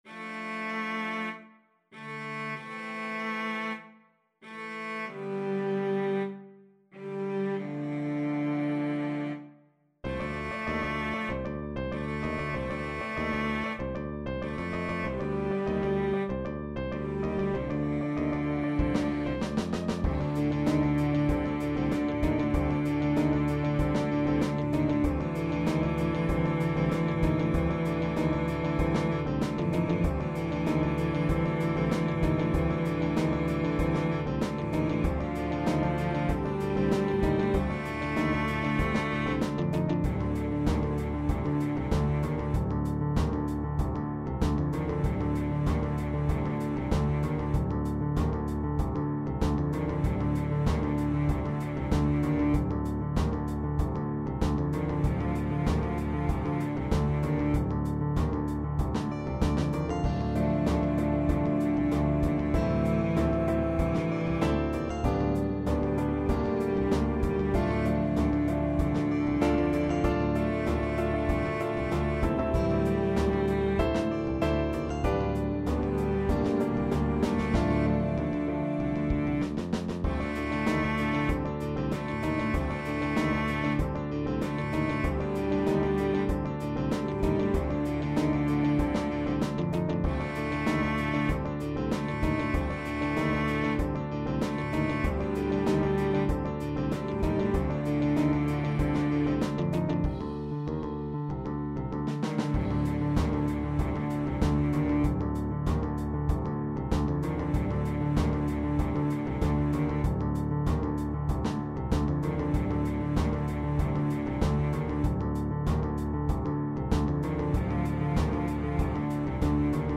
Engel (bariton)
ENGEL_bariton.mp3